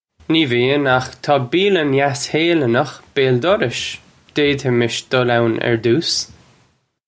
Pronunciation for how to say
Nee vee-in, akh taw bee-ya-lun yass Hay-lunnukh bayl dorrish. Dade-imish dull own urr doos.
This comes straight from our Bitesize Irish online course of Bitesize lessons.